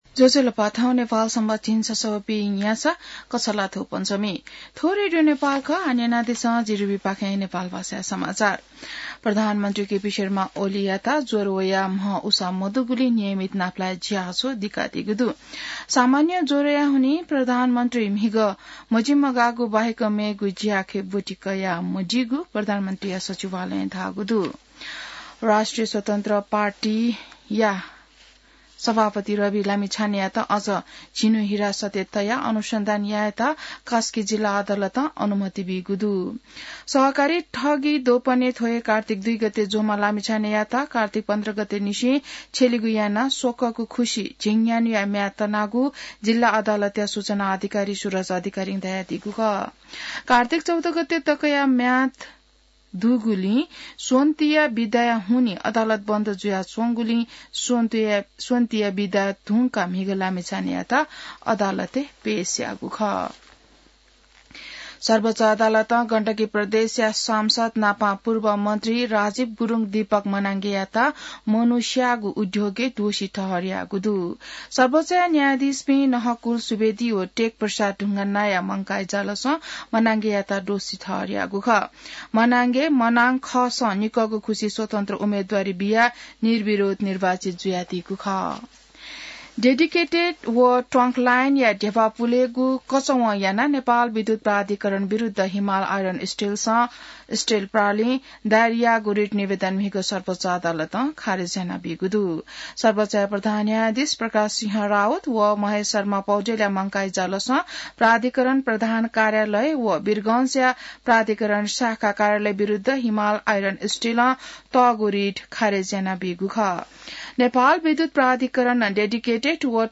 नेपाल भाषामा समाचार : २२ कार्तिक , २०८१